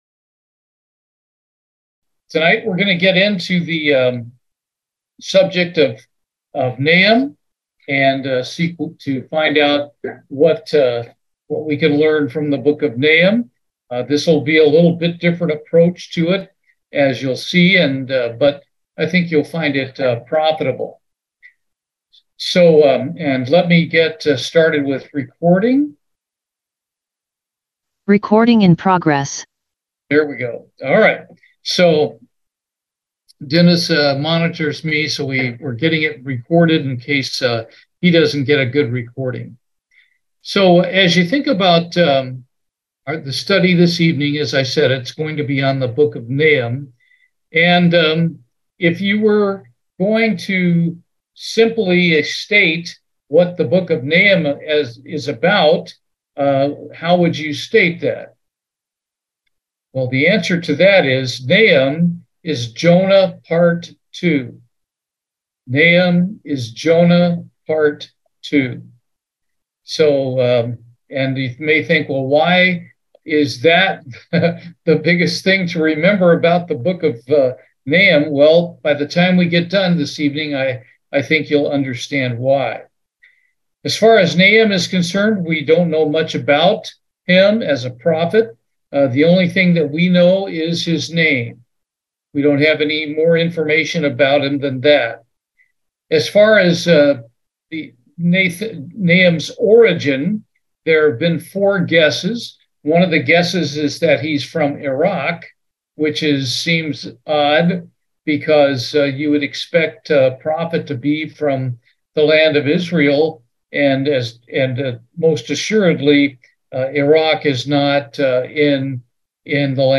Bible Study, Nahum